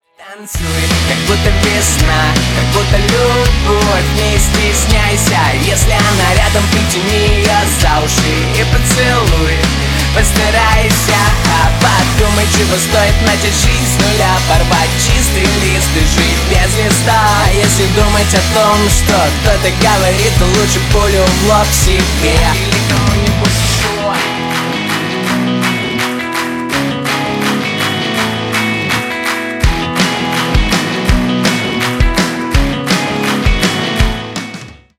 Рок Металл
весёлые